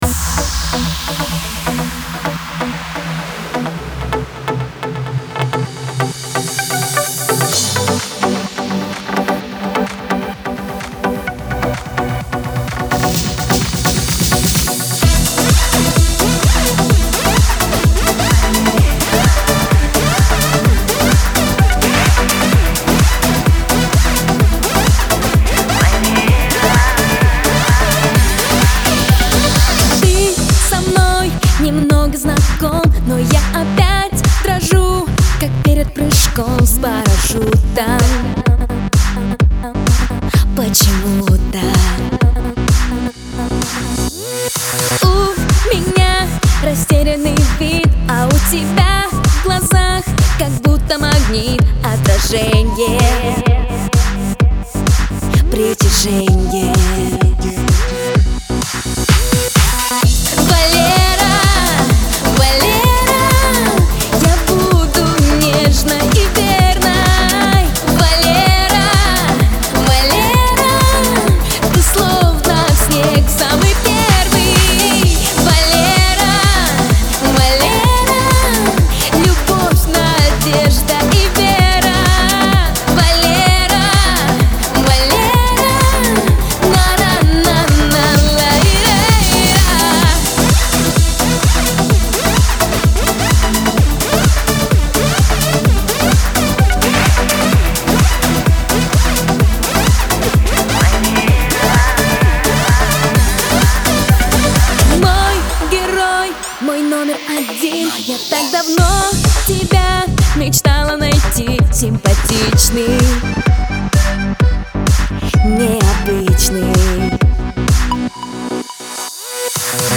Спасибо, но это ремикс.